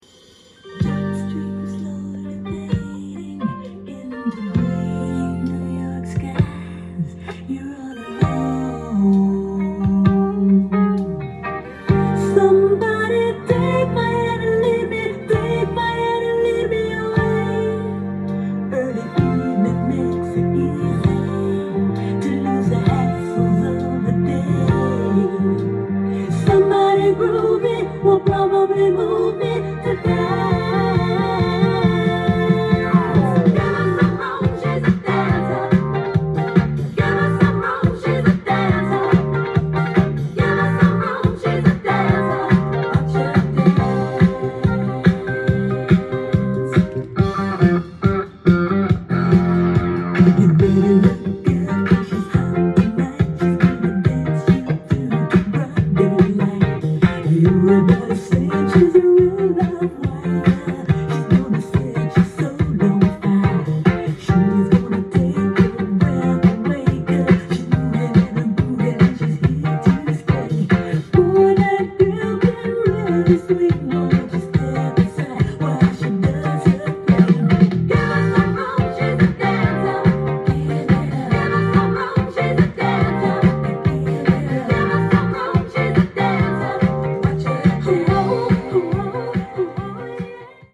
single
店頭で録音した音源の為、多少の外部音や音質の悪さはございますが、サンプルとしてご視聴ください。